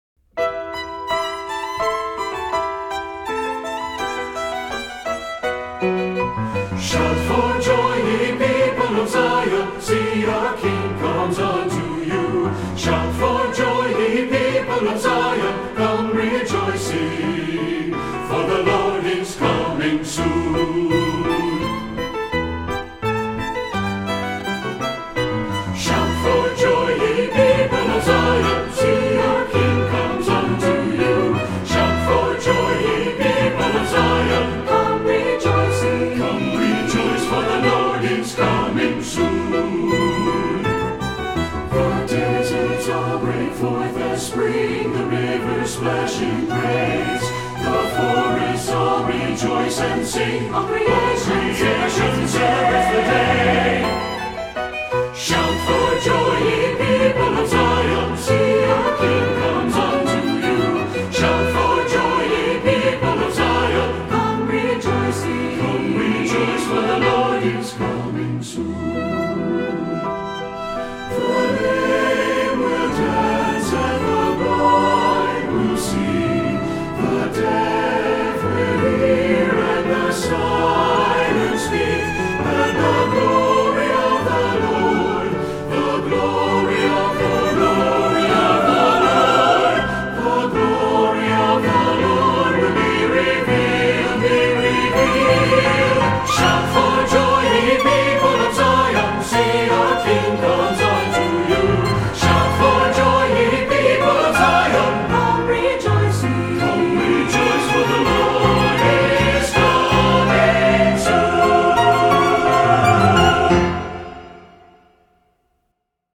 SAB Church Choir Music
Voicing: SATB and Violin